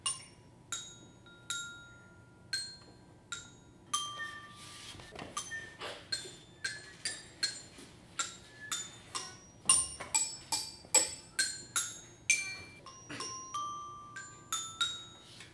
Tous les 6ème ont enregistré le même morceau d'un chant de Noël " We wish you a merry Chistmas ".